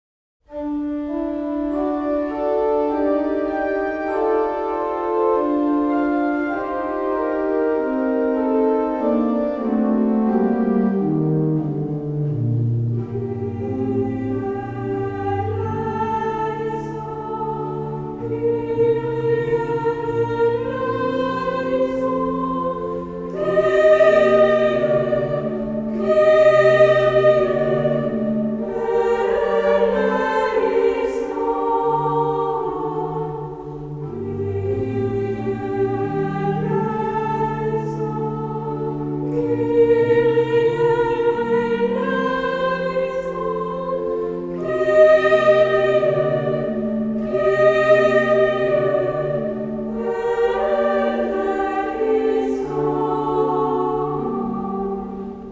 2 et 3 voix égales + orgue
Audios : version d'origine pour voix égales et orgue